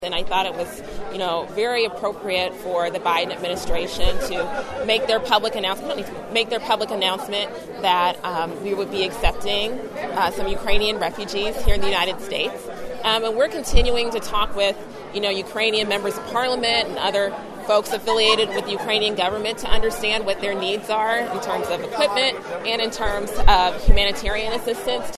She talked about Ukraine in an interview at the LaSalle County Democratic Party’s annual dinner last Saturday.